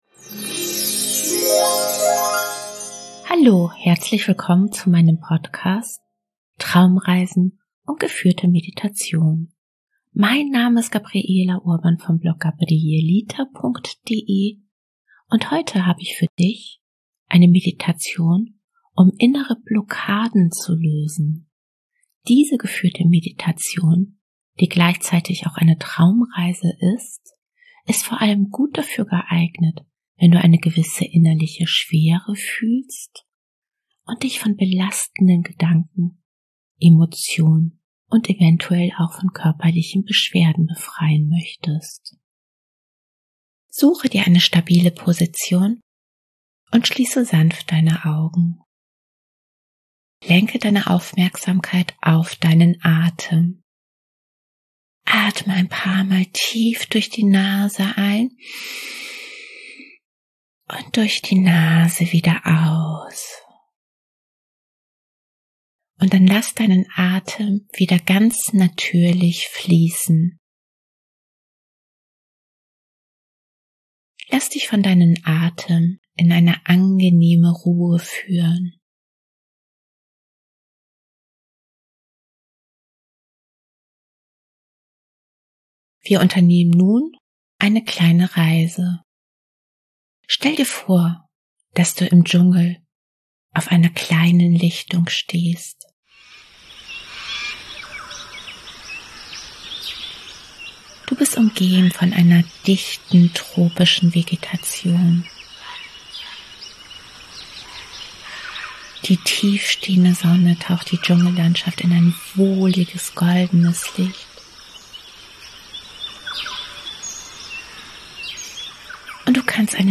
Traumreisen & geführte Meditationen